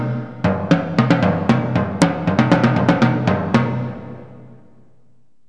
tom_tom.mp3